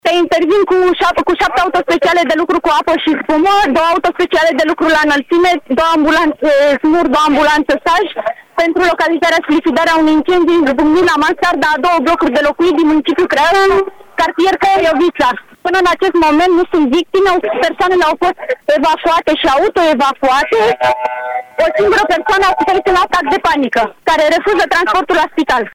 de la fața locului: